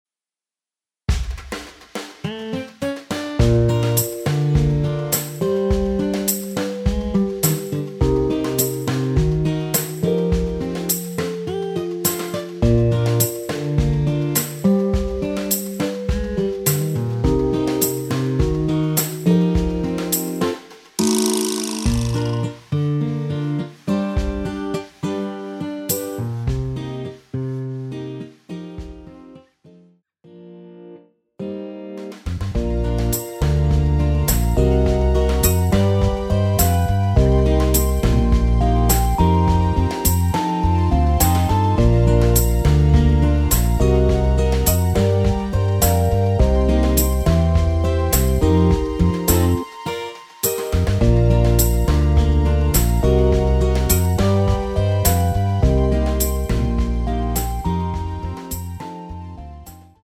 대부분의 여성분이 부르실수 있는 키로 제작 하였습니다.
Bb
앞부분30초, 뒷부분30초씩 편집해서 올려 드리고 있습니다.
중간에 음이 끈어지고 다시 나오는 이유는